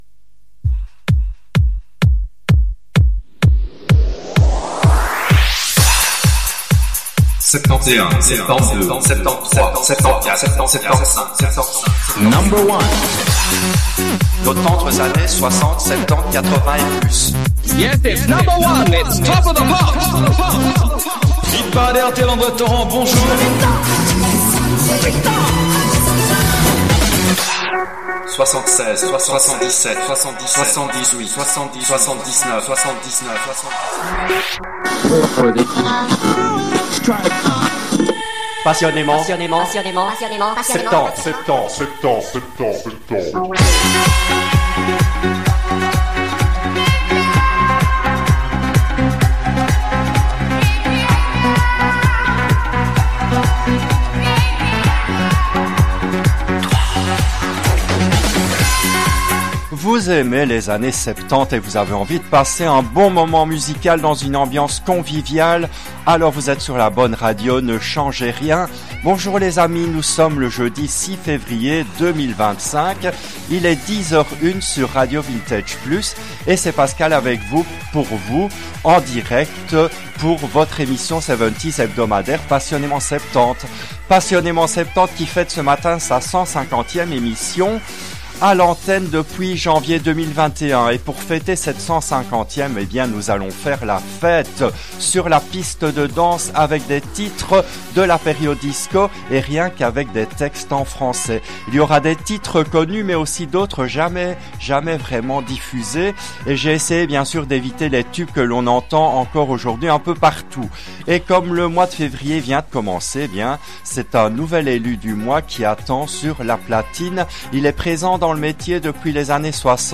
qui a été diffusée en direct le jeudi 06 février 2025 à 10h depuis les studios belges de RADIO RV+.
enchaîne des titres DISCO en Français qui datent de la fin des années 70’s